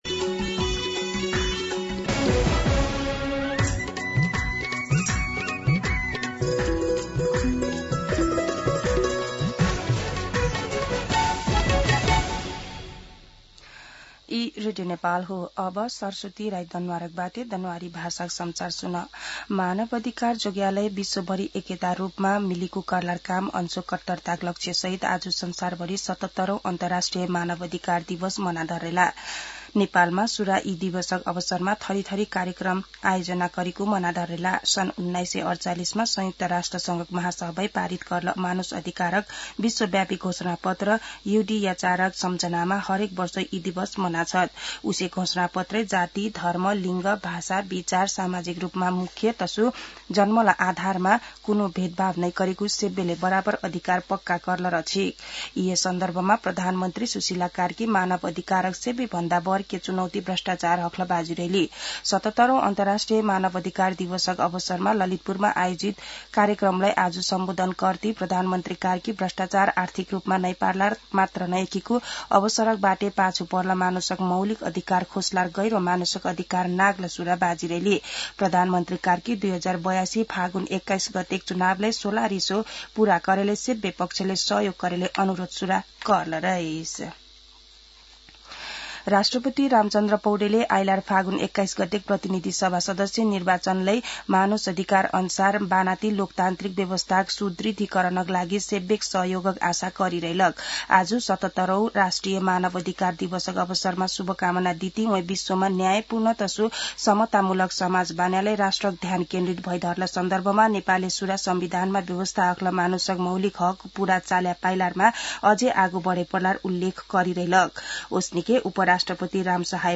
दनुवार भाषामा समाचार : २४ मंसिर , २०८२
Danuwar-News-08-24.mp3